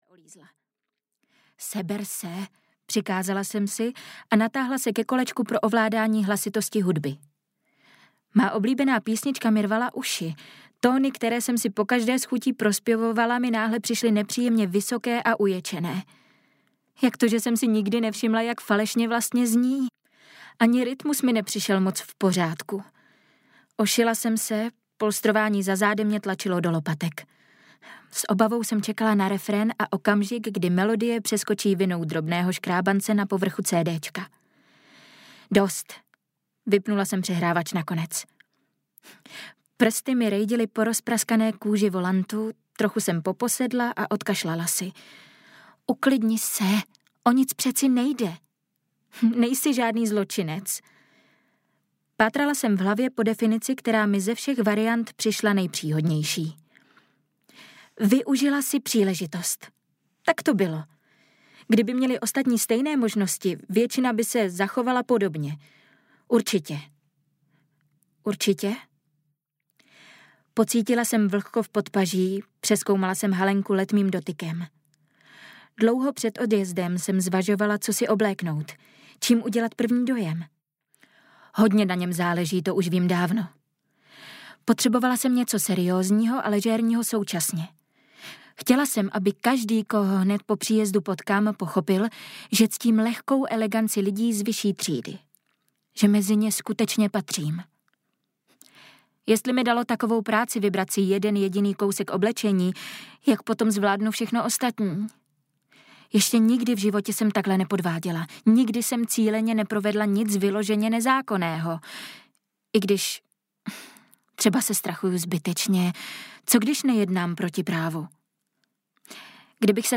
Dokonalé městečko audiokniha
Ukázka z knihy